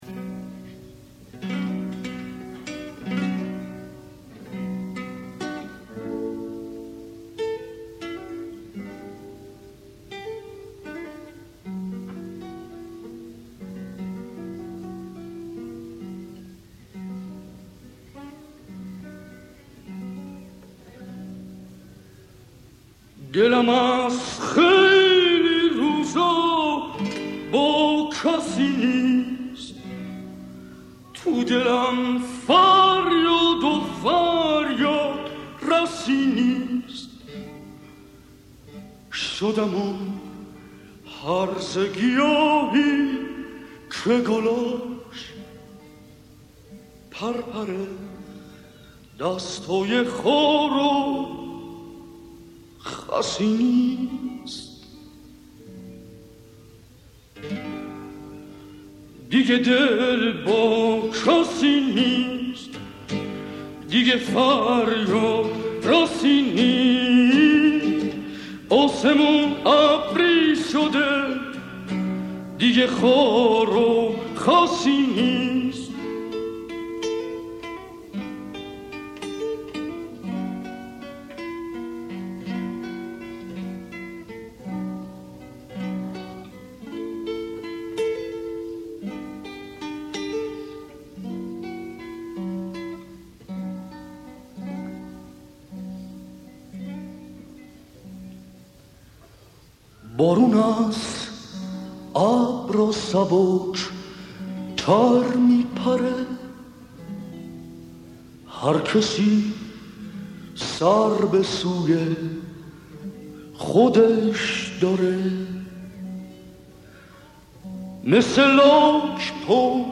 آهنگ قدیمی
غمگین قدیمی